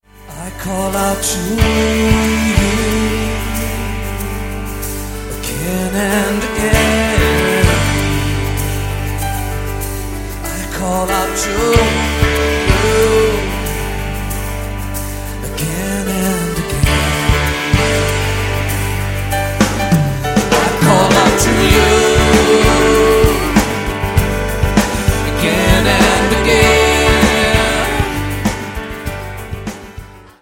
25 modern worship favorites
• Sachgebiet: Praise & Worship